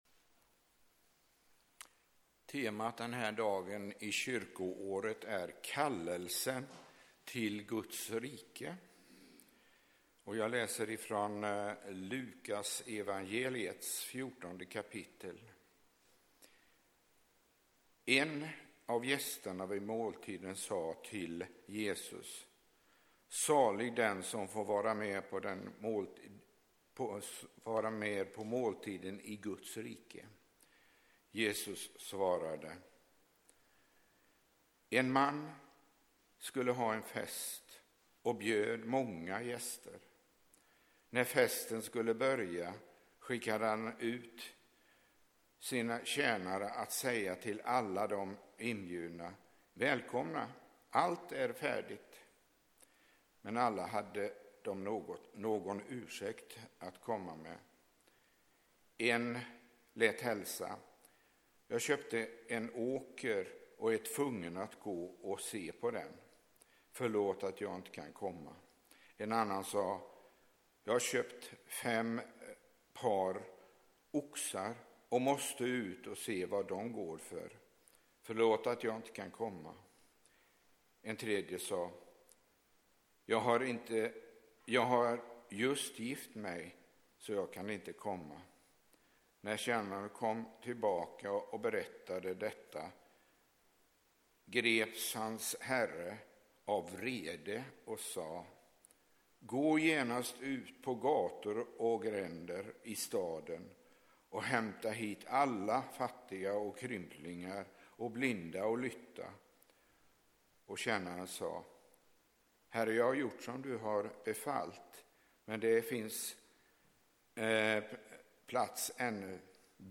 predikar i gudstjänsten 9 juni.